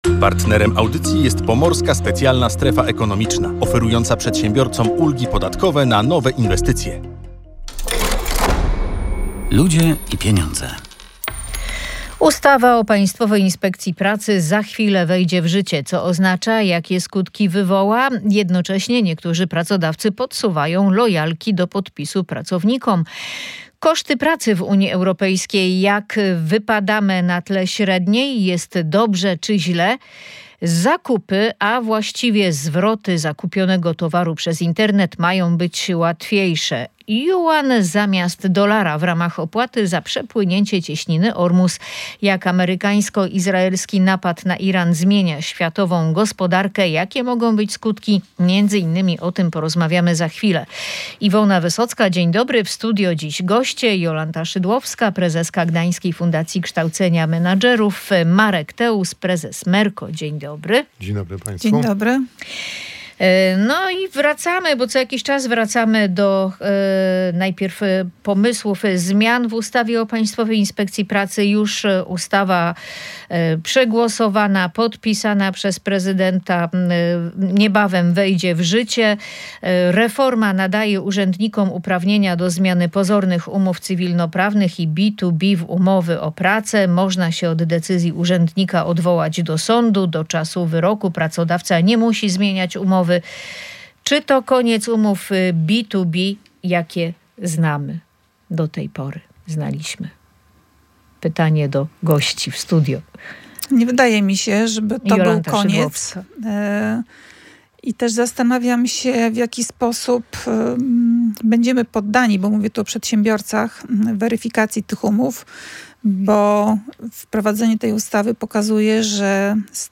Między innymi na ten temat rozmawialiśmy w audycji „Ludzie i Pieniądze”.